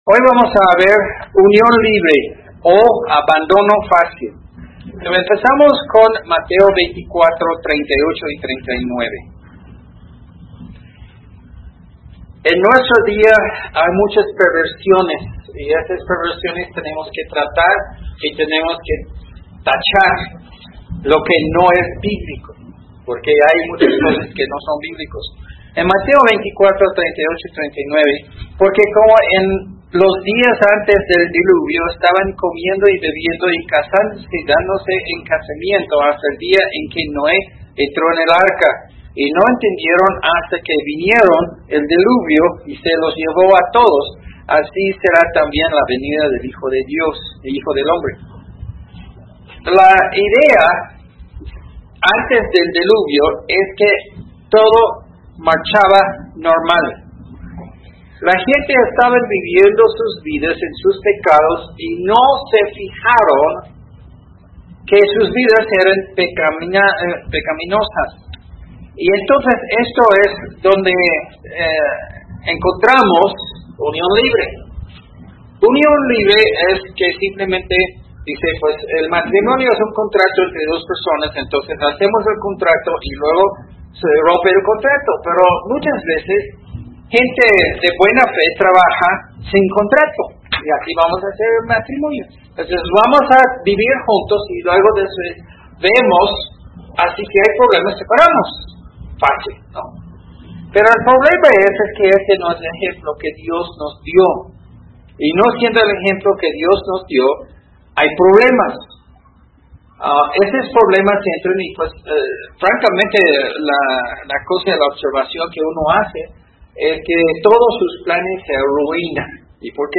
fam12 ¿Unión Libre? o ”¿Abandono Fácil?” - Sermones